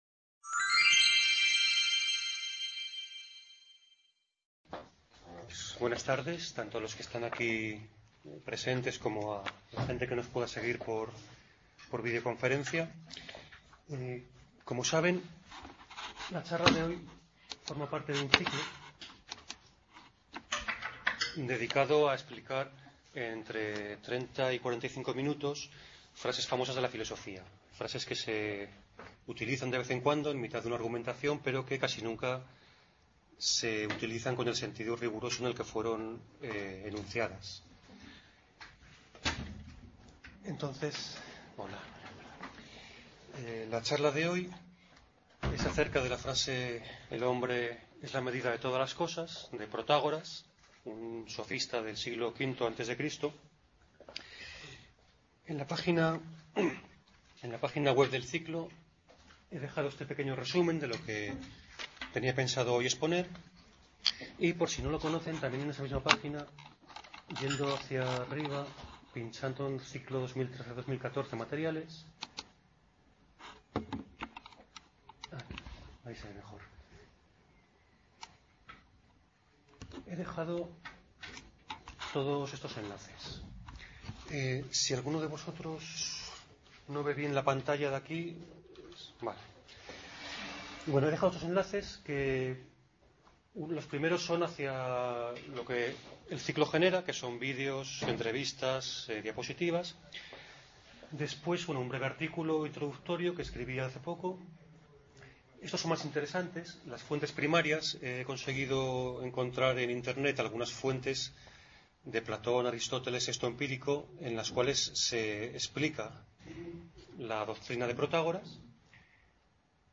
"Frases de los filósofos" es un ciclo que se compone de ocho conferencias divulgativas sobre aspectos de la Filosofía.